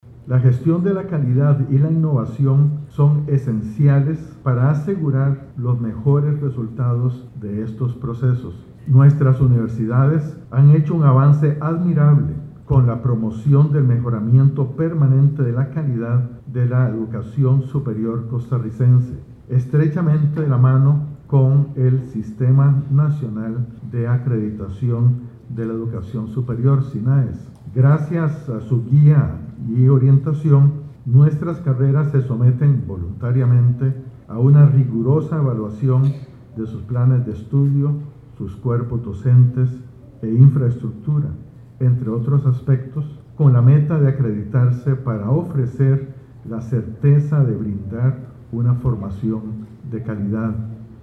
Dentro de su discurso inaugural, el Dr. Henning Jensen, rector de la Universidad de Costa Rica, destacó que es indispensable compartir procesos de evaluación exitosos para orientar mejor los objetivos de calidad en la educación superior pública costarricense.